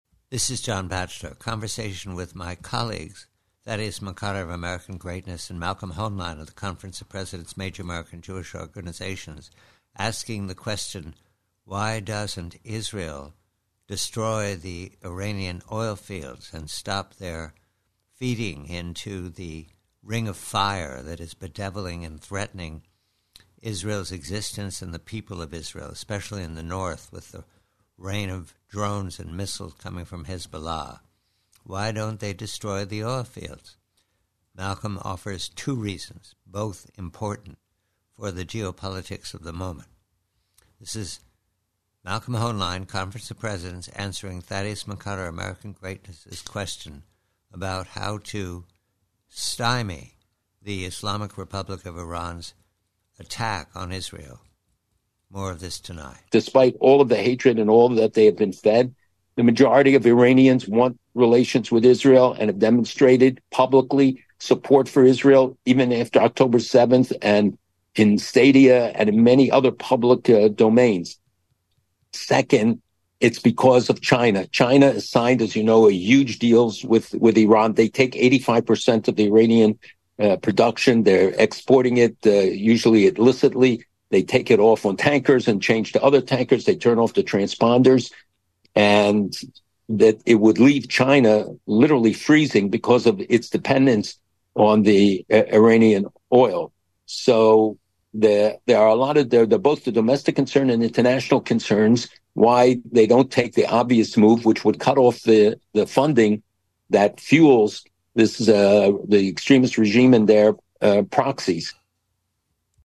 PREVIEW: OIL, IRAN & PRC: Conversation with colleagues Thaddeus McCotter and Malcolm Hoenlein re why the IAF does not destroy Iran's oil field infrastructure in order to shut down the war machine called the Ring of Fire.